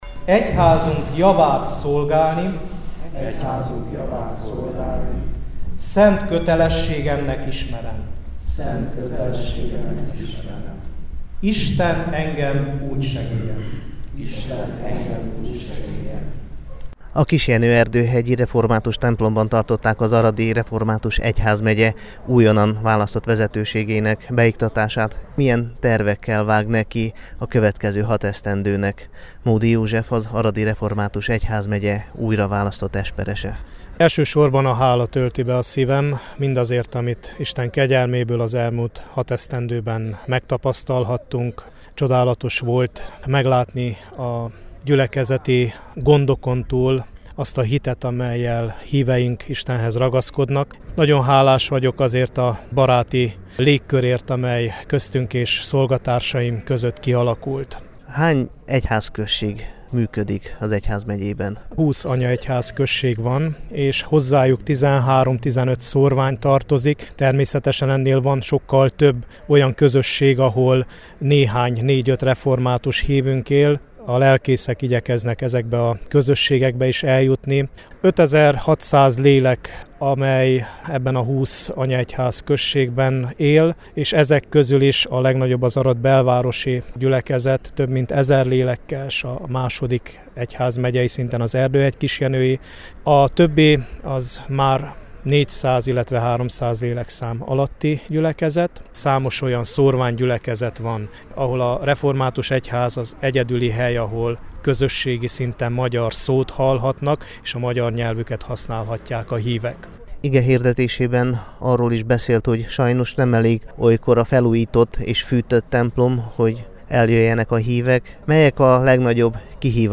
Kettős ünnep a kisjenői református gyülekezetben [AUDIÓ]
Kettős ünnepet tartottak szombaton az Arad megyei Kisjenőben. A református templomban tartották meg az Aradi Református Egyházmegyében tartott tisztújítás nyomán némileg megváltozott összetételű vezetőség beiktatását és fogadalomtételét, ezt követően pedig megáldották a templom felújított orgonáját.
esperes-beiktatas_es_orgonaszenteles_kisjenoben.mp3